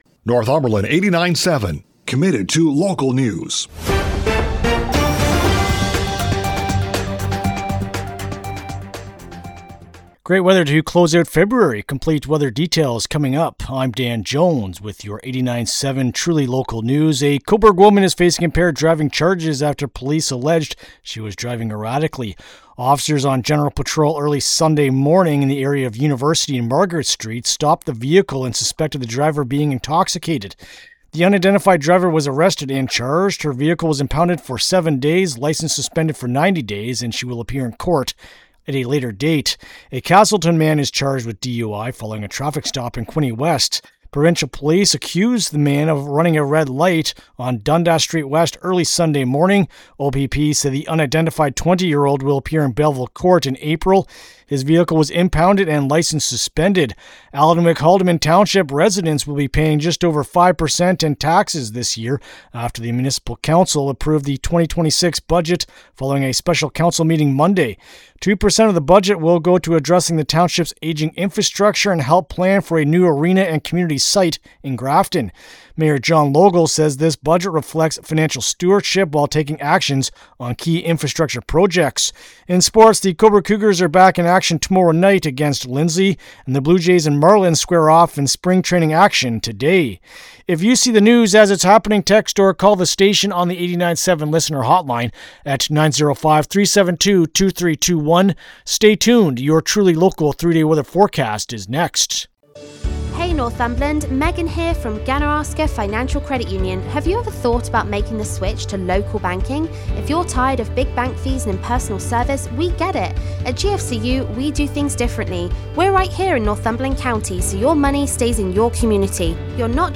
Thursday-Feb-26-AM-News-2.mp3